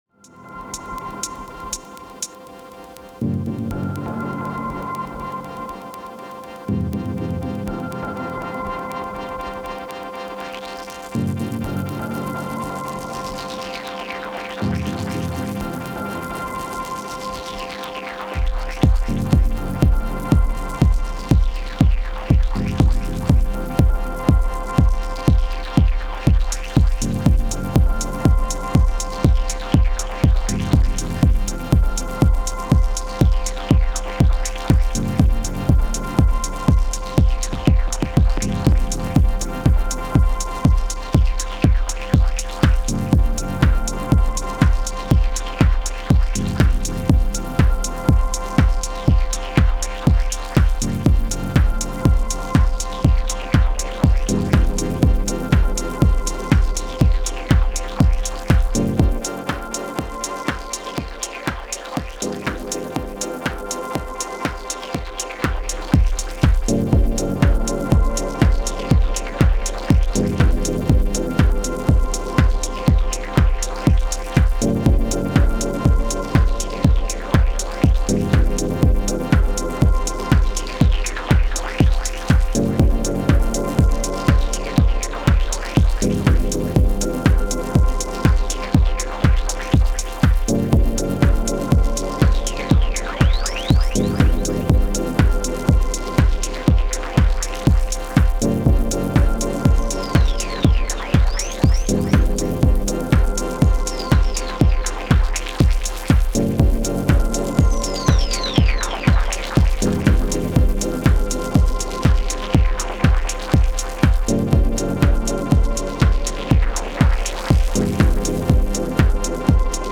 Trying to incorporate more instruments with Pulsar-23, Labyrinth and OT for dub stab and hi-hat.